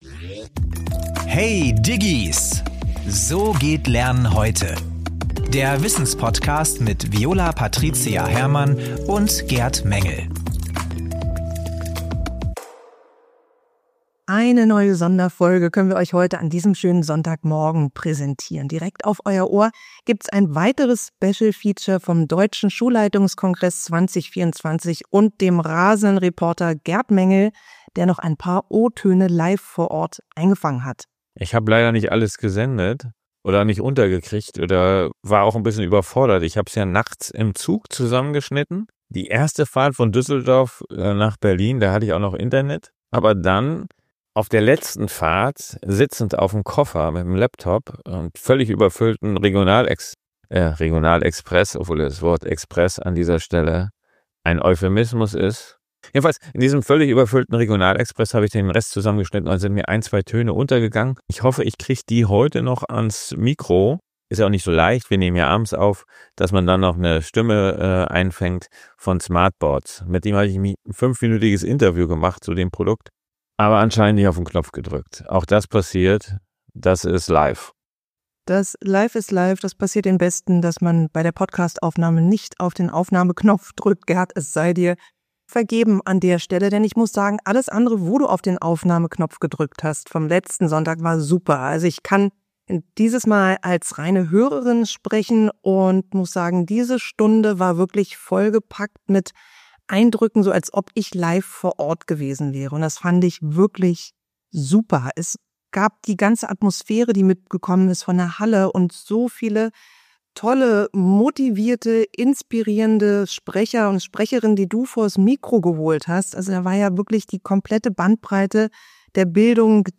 Mit authentischen Stimmen, inspirierenden Gesprächen und überraschenden Momenten fängt sie die lebendige Atmosphäre des größten Bildungsevents Deutschlands ein.
•Herausforderungen unterwegs: Wie diese Folge zwischen Bahnhöfen und in einem überfüllten Regionalexpress entstanden ist.